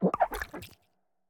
Cri d'Olivini dans Pokémon Écarlate et Violet.